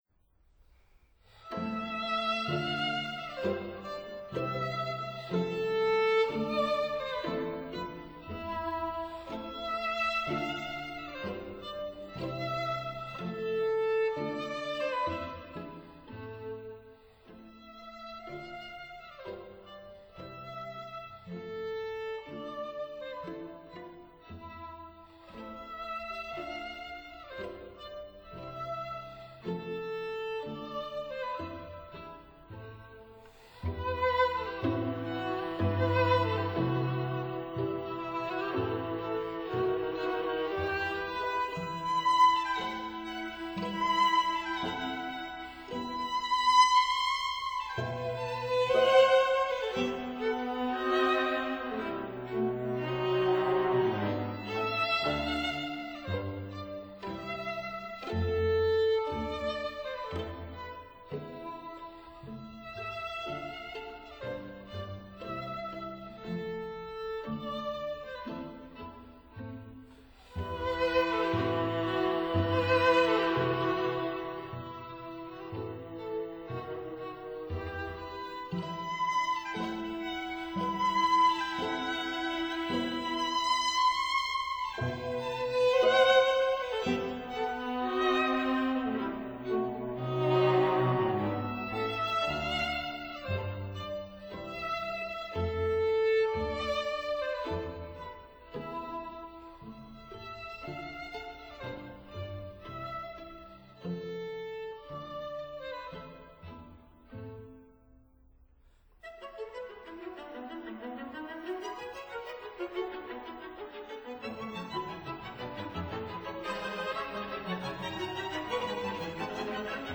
String Quartets by:
(Period Instruments)